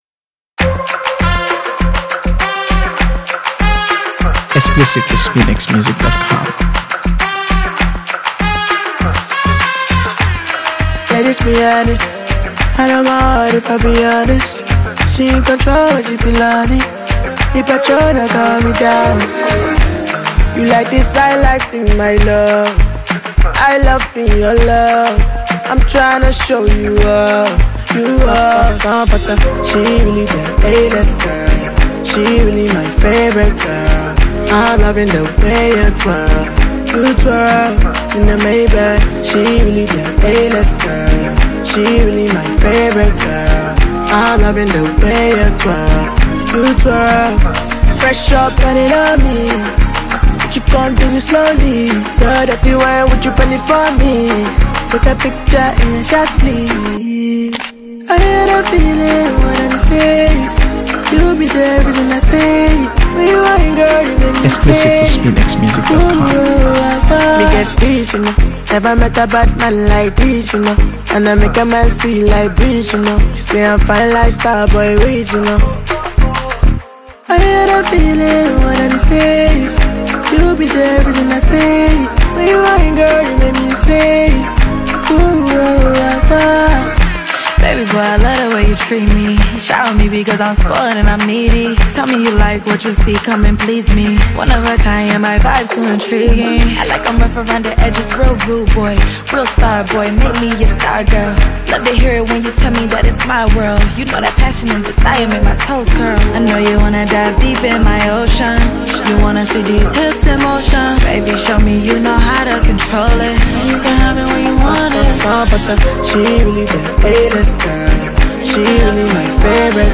AfroBeats | AfroBeats songs
blend of smooth rap and melodic delivery